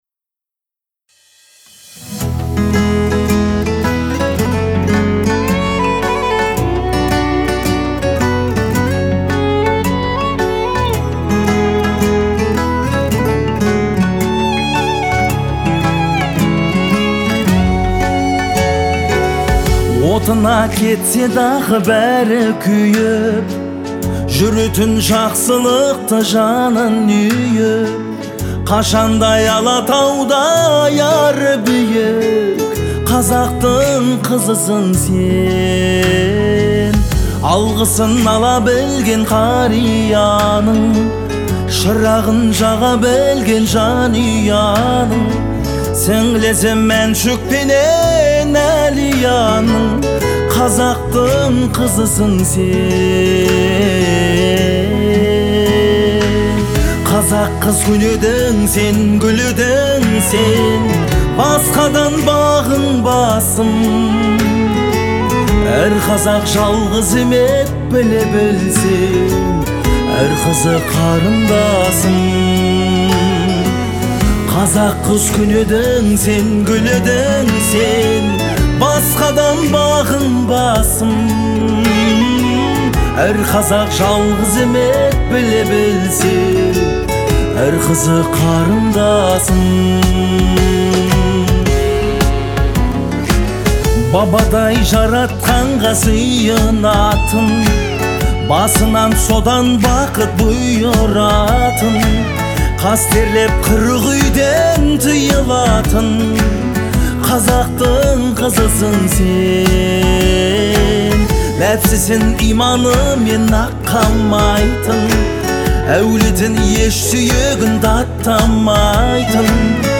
это яркая и эмоциональная песня в жанре казахской поп-музыки